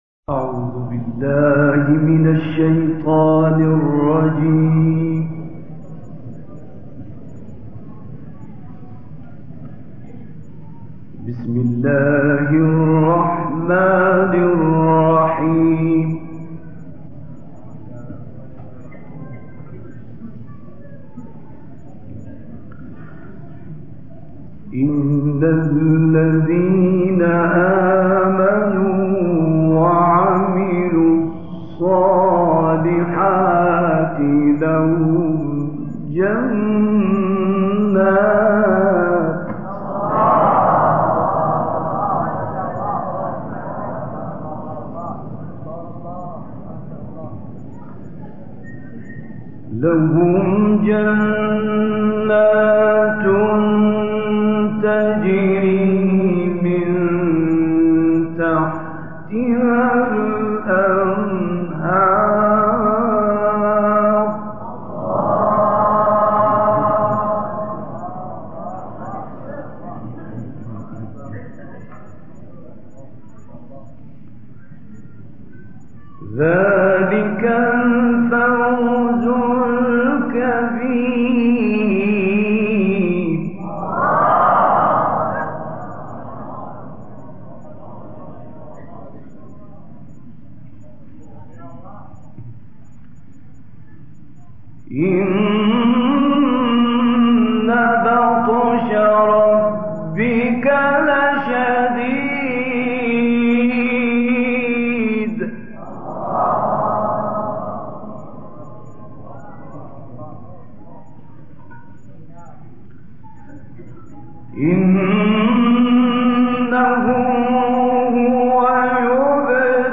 تلاوت آیاتی از سوره بروج و سوره های شمس و تین توسط مرحوم راغب مصطفی غلوش